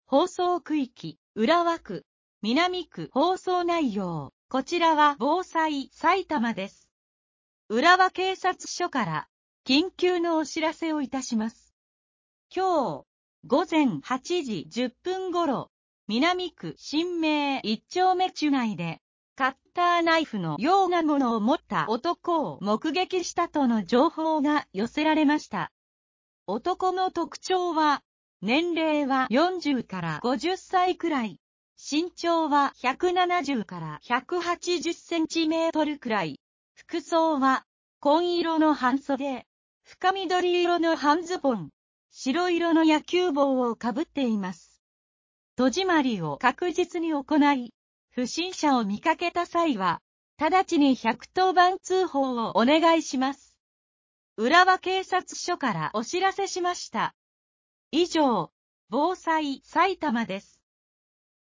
警察からの緊急放送 | 埼玉県さいたま市メール配信サービス